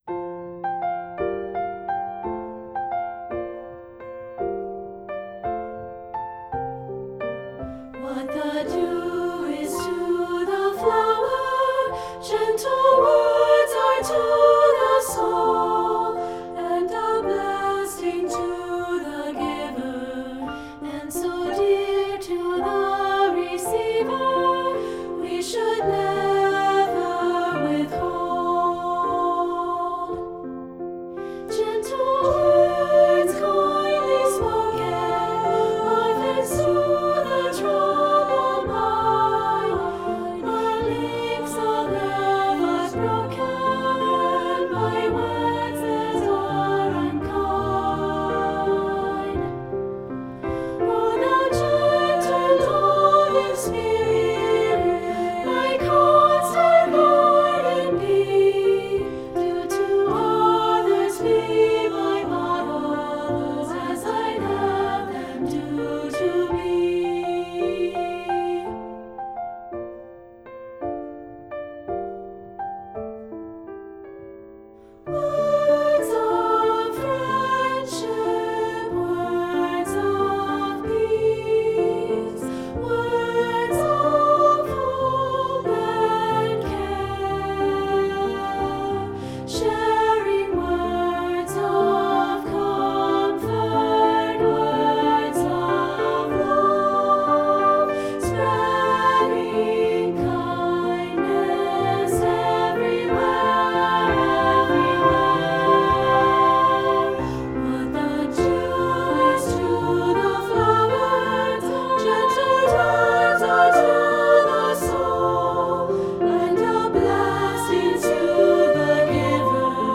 Voicing: Unison/two